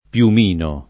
piumino [ p L um & no ] s. m.